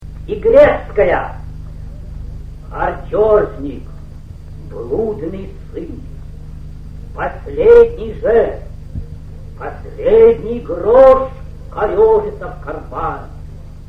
Записи 1951г, квартира Л.Ю.Брик: